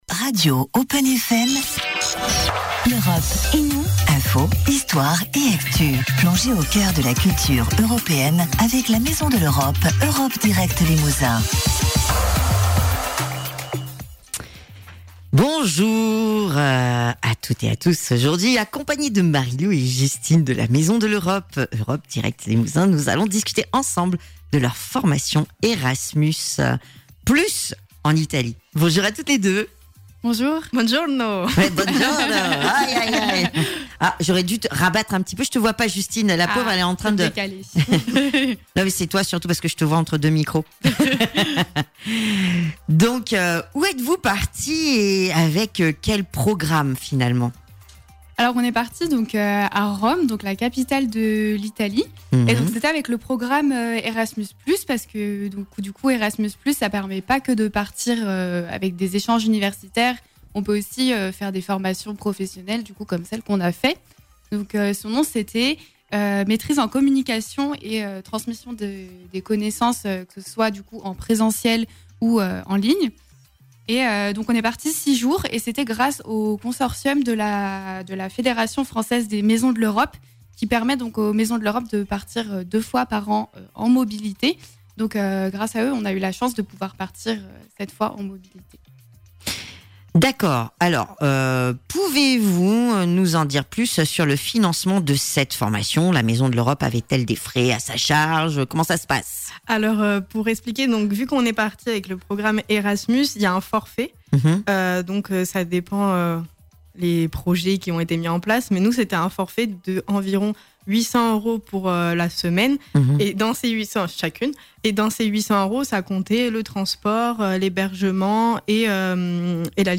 Elle nous racontent leur expérience : qu’ont-elles appris ? comment pourront-elles l’appliquer dans leurs missions ? quels monuments ont-elles visité ? Découvrez leur témoignage !